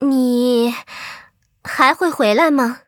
文件 文件历史 文件用途 全域文件用途 Erze_fw_01.ogg （Ogg Vorbis声音文件，长度0.0秒，0 bps，文件大小：36 KB） 源地址:游戏语音 文件历史 点击某个日期/时间查看对应时刻的文件。